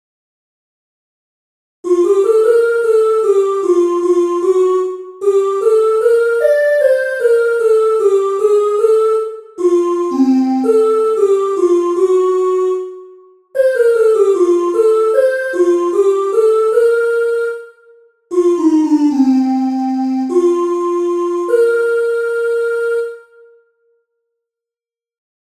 Entoación a capella
Imos practicar a entoación a capella coas seguintes melodías.
entonacion7.2capela.mp3